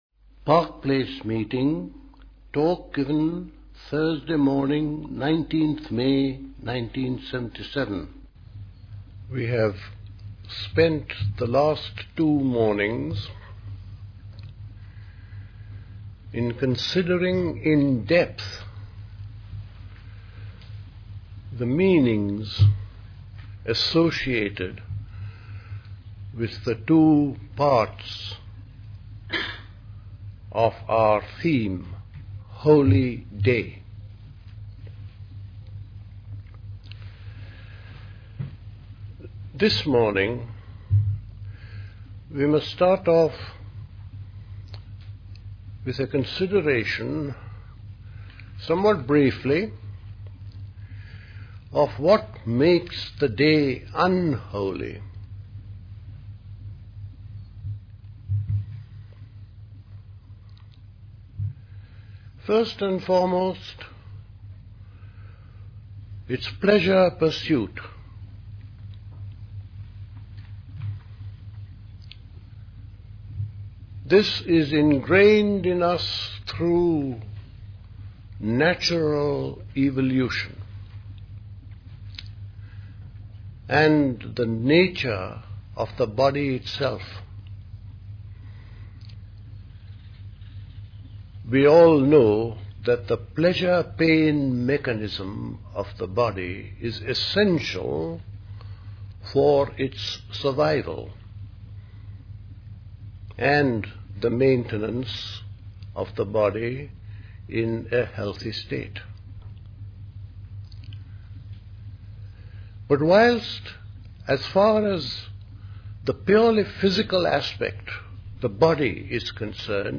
A talk
at Park Place Pastoral Centre, Wickham, Hampshire